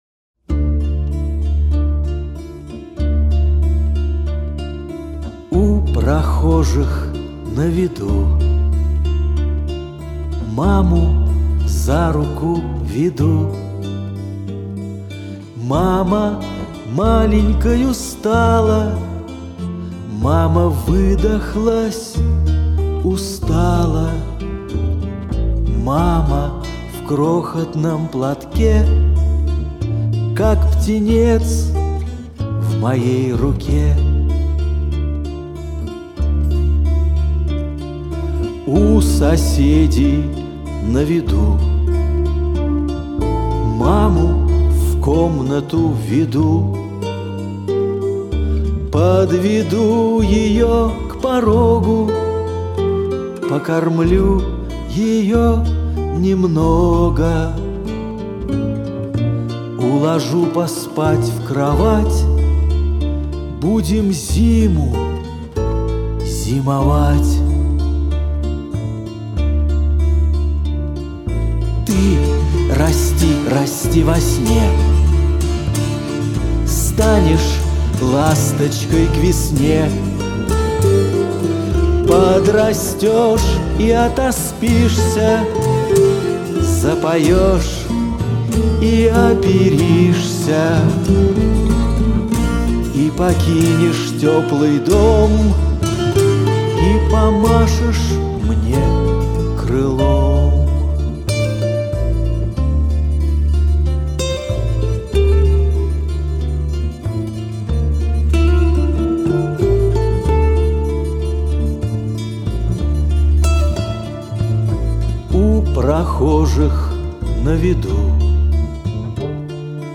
музыка и исполнение